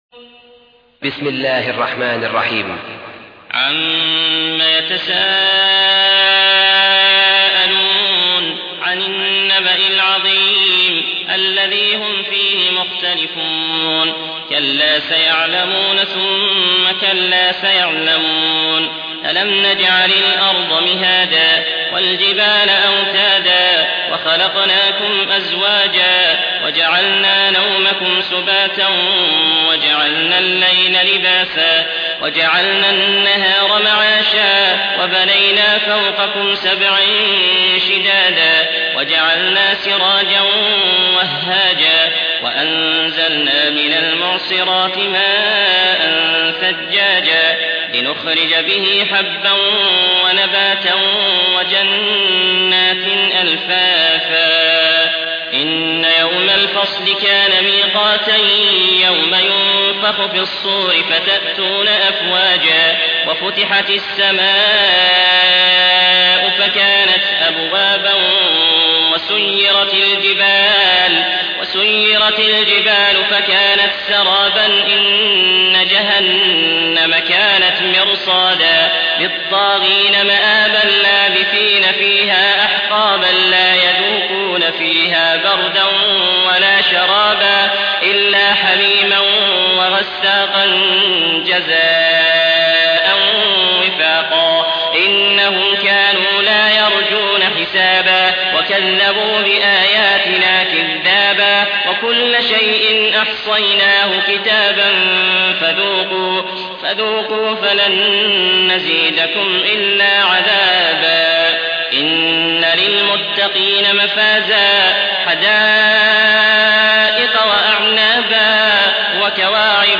Home Of Hausa Translation Of The Holy Quran Recitation